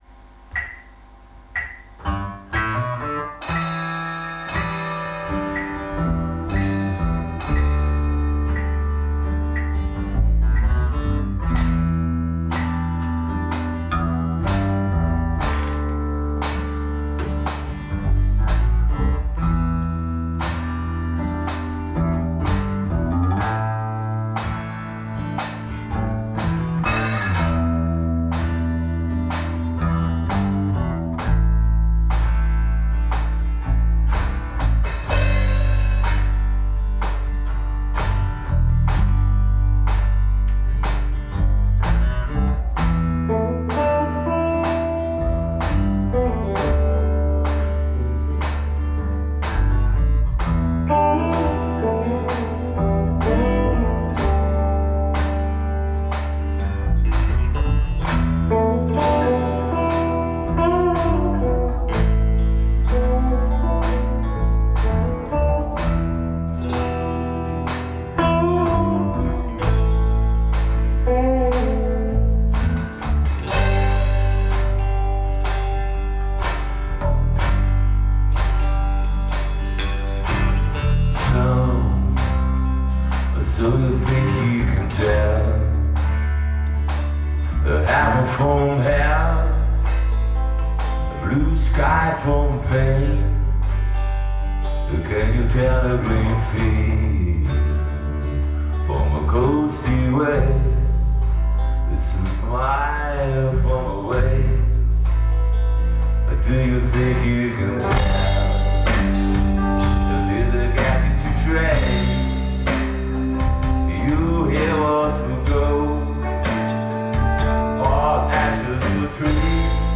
Probenaufnahme am 17.